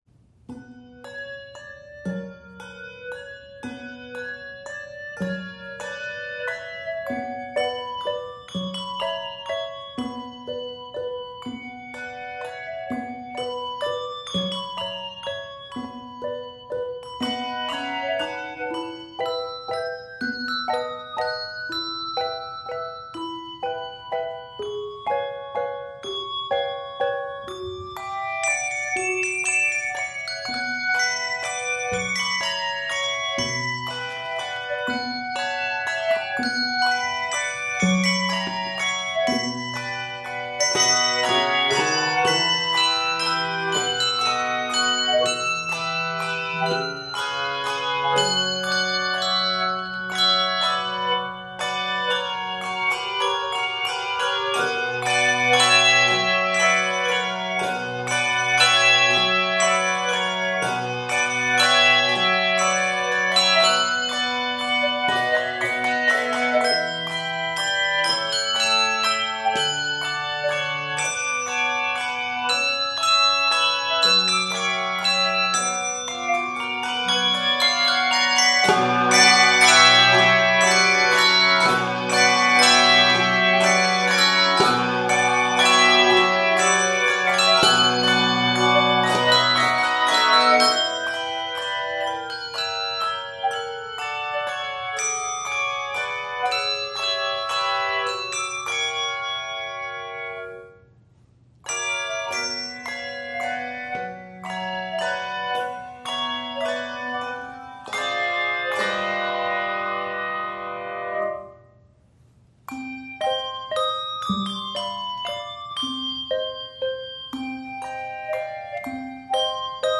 handbells
is set here in a minor mode, at a slow lilting waltz tempo